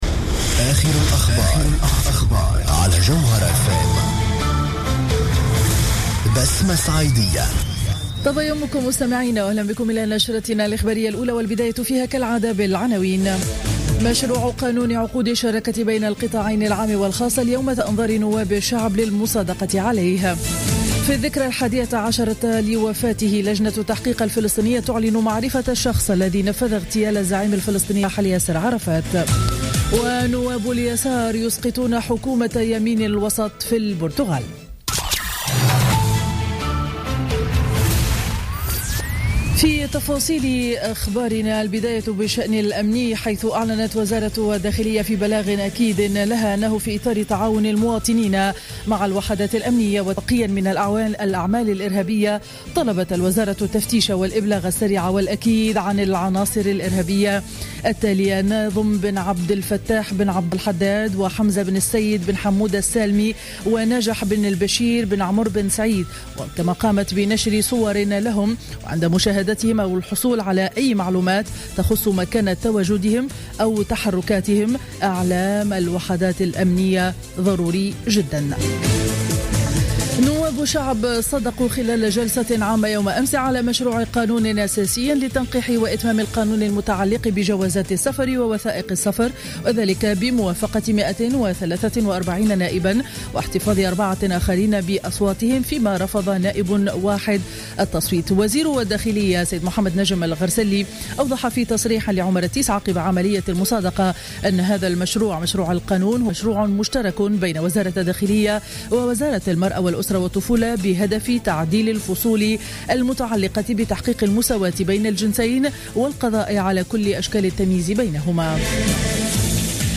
نشرة أخبار السابعة صباحا ليوم الأربعاء 11 نوفمبر 2015